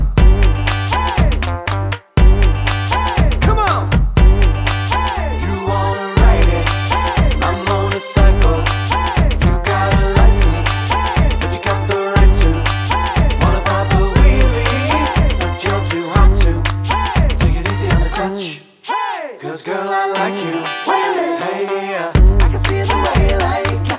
for solo male Pop (2010s) 3:22 Buy £1.50